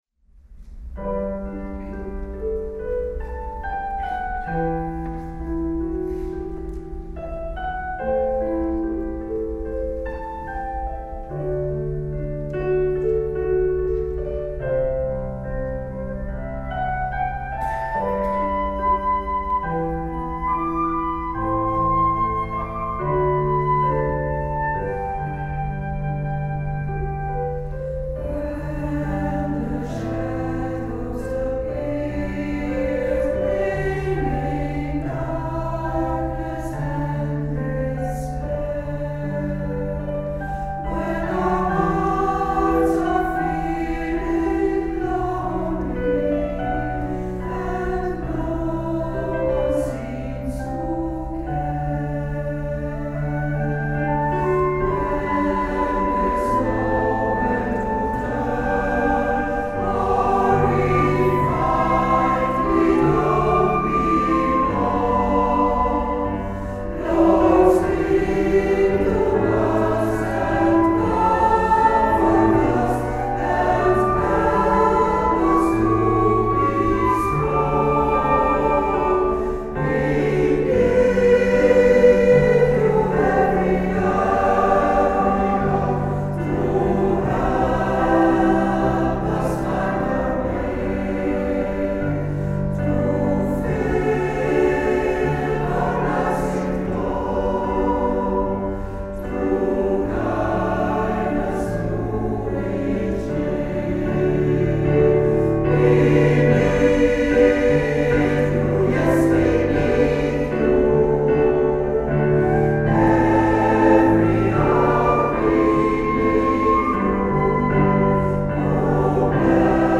Kerstconcert Dreaming of a white Christmas
Kerk St.-Franciscus Heverlee
Piano en orgel
Dwarsfluit